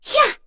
Kara-Vox_Attack3.wav